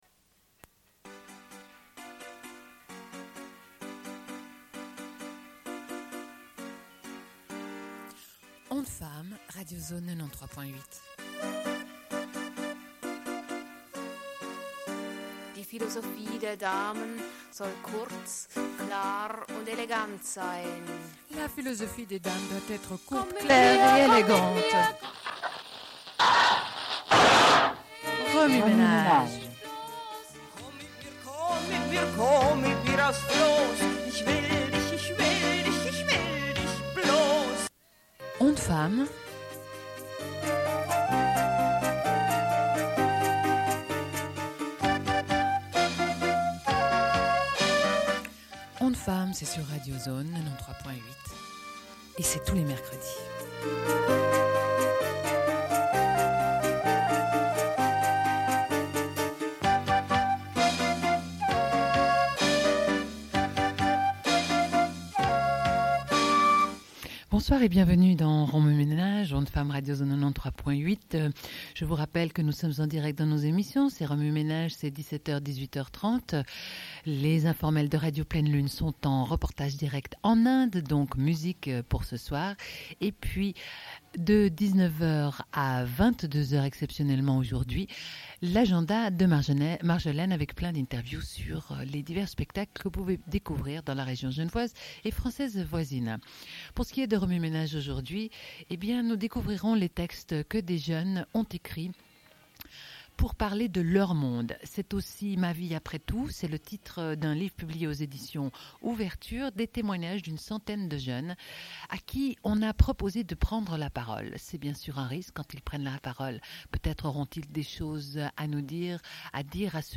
Sommaire de l'émission : au sujet du livre C'est aussi ma vie après tout ! : des jeunes (d)écrivent leur monde : témoignages d'une centaine de jeunes, Editions Ouverture, 1997. Lecture d'extraits.
Une cassette audio, face A